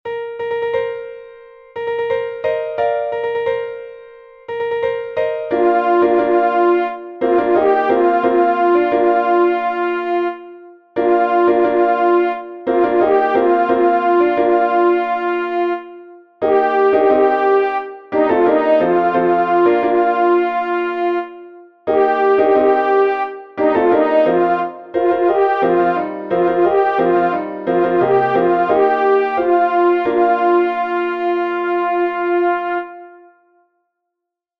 ALTO
processional-fanfare-alto.mp3